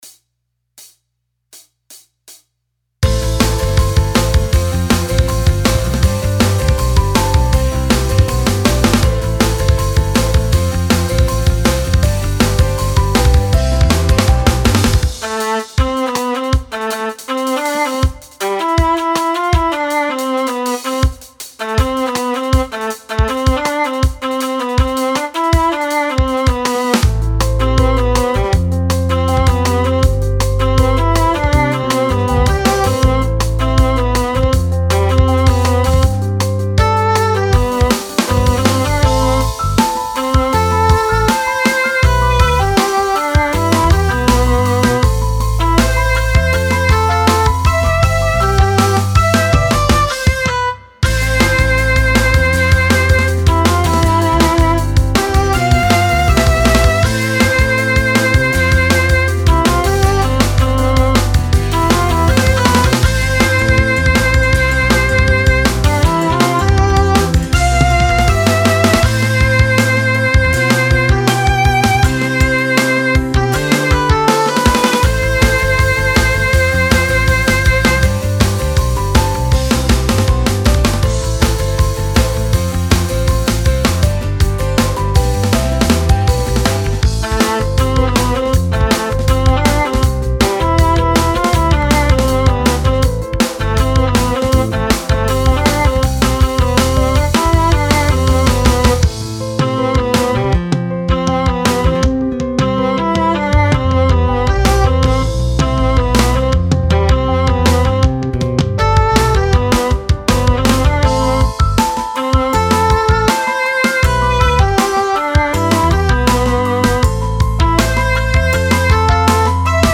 ちなみにボカロの歌はまだ入ってません。
ピアノ・ロックの明るめの楽曲デモ
2015年くらいに制作したピアノ・ロック系の楽曲です。
明るめの曲調になっています。